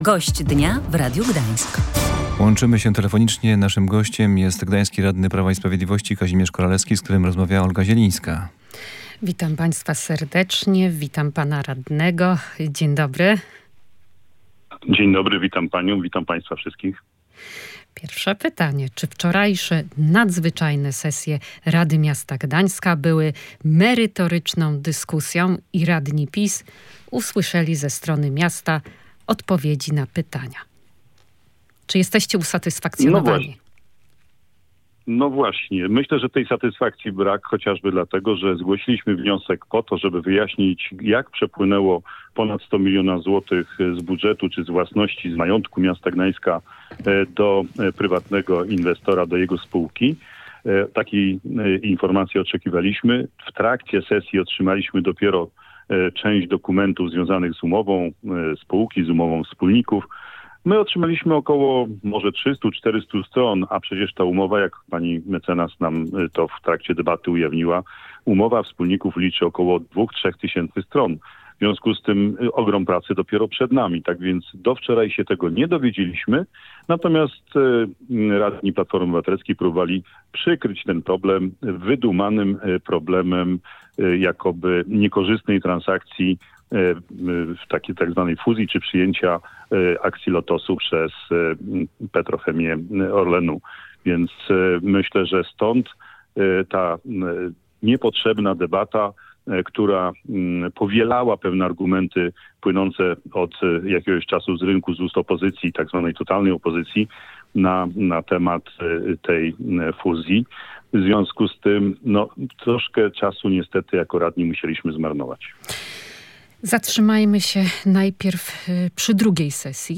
Nie uzyskaliśmy odpowiedzi na najważniejsze pytania podczas czwartkowej nadzwyczajnej sesji, poświęconej nieprawidłowościom przy budowie Forum Gdańsk – mówił gość Radia Gdańsk, szef klubu radnych PiS Kazimierz Koralewski.